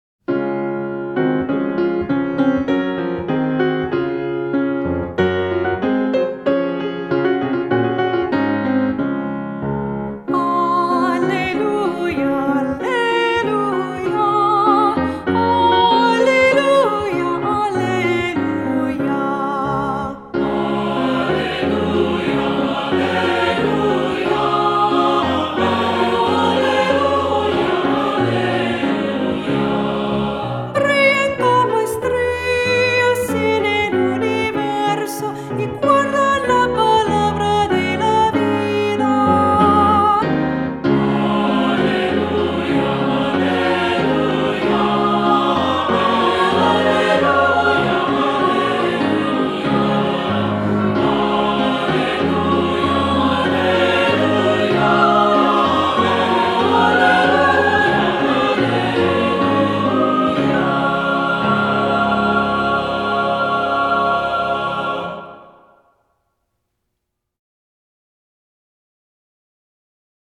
Voicing: Assembly,SATB, descant, cantor